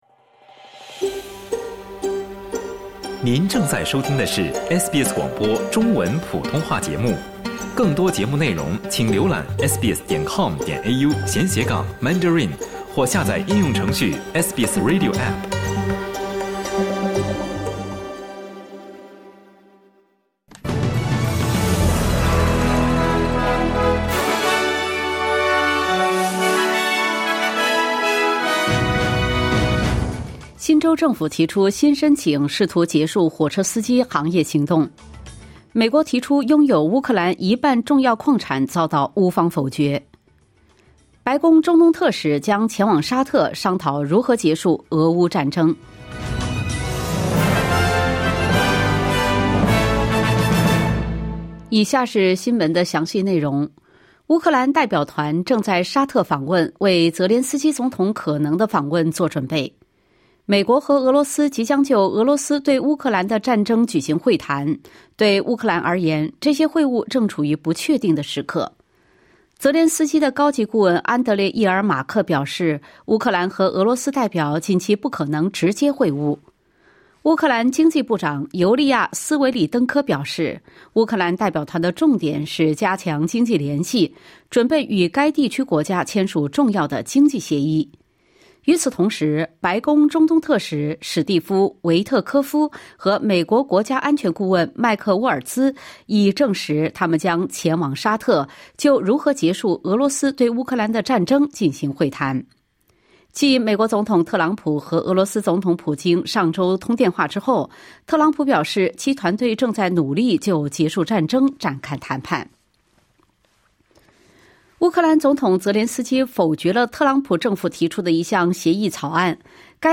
SBS早新闻（2025年2月17日）